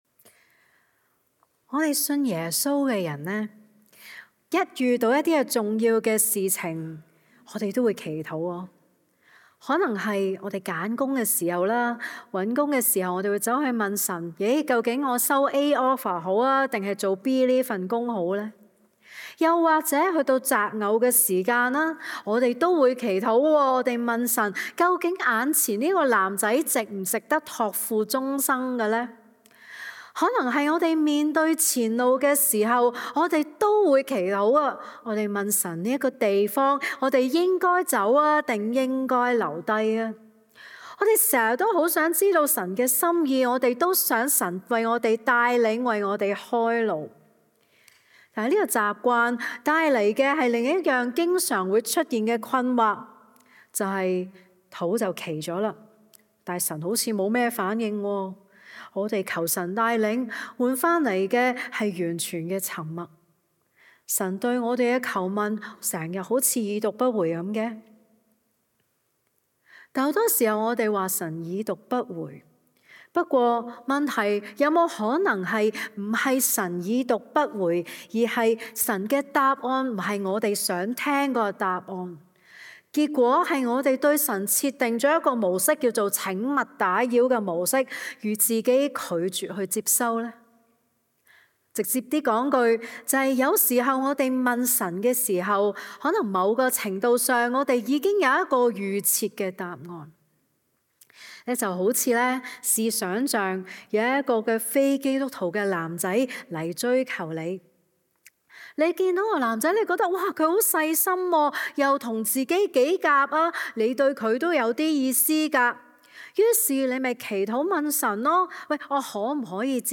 下載 下載 歌鄰基督教會 本週報告事項 (Mar 28, 2026) 已讀不回 Current 講道 已讀不回 (3) 主呀，俾個 like 我！